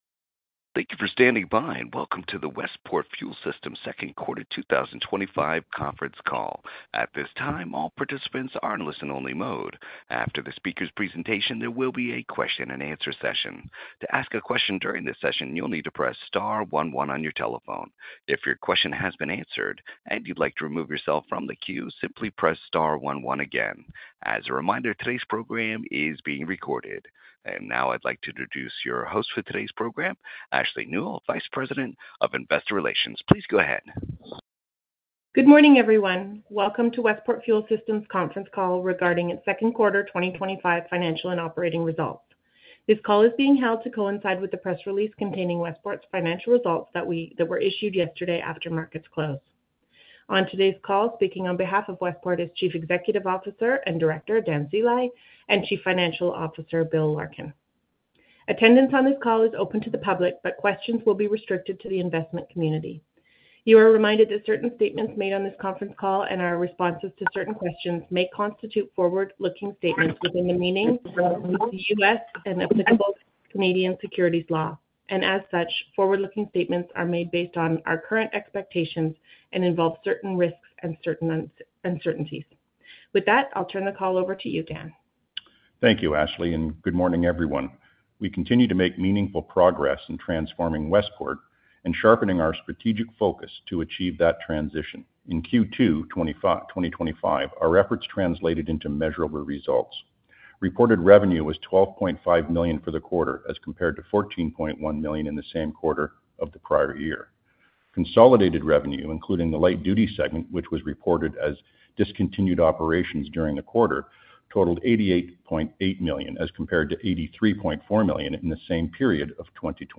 Westport Fuel Systems - Westport Fuel Systems Q2 2025 Earnings Conference Call